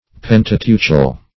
Pentateuchal \Pen`ta*teu"chal\, a. Of or pertaining to the Pentateuch.
pentateuchal.mp3